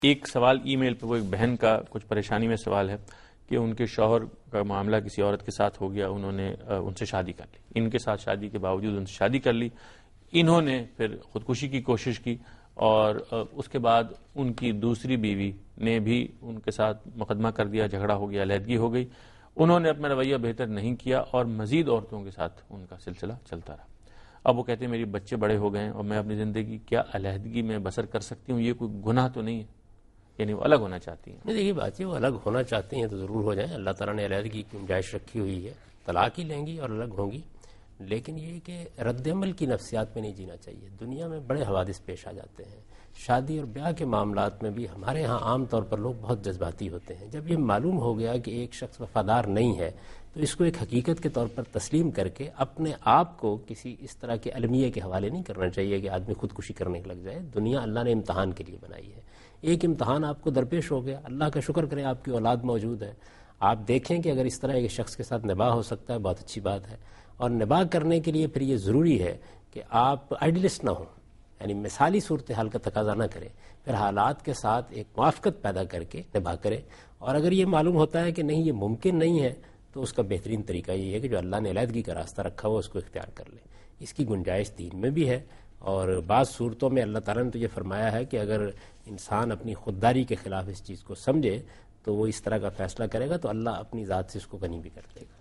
دنیا نیوز کے پروگرام دین و دانش میں جاوید احمد غامدی ”آزمایش اور ہمارا رویہ“ سے متعلق ایک سوال کا جواب دے رہے ہیں